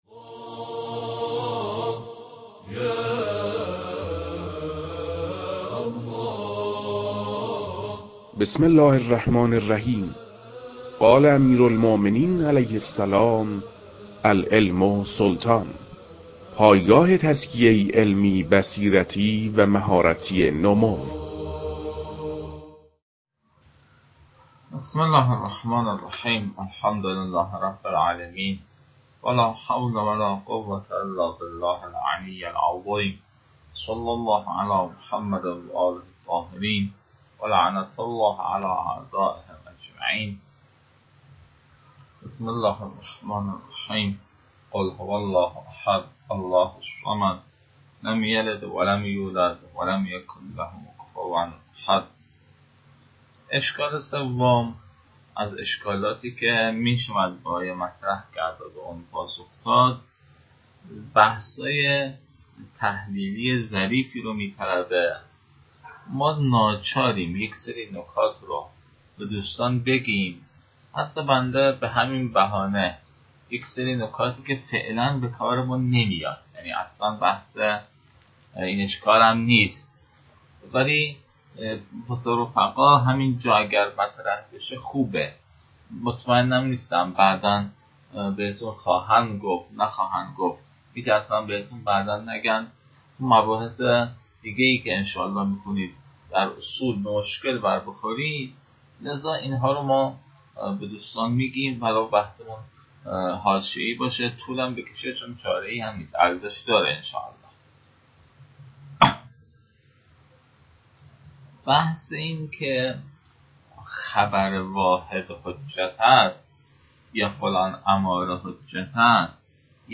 فایل های مربوط به تدریس مبحث رسالة في القطع از كتاب فرائد الاصول متعلق به شیخ اعظم انصاری رحمه الله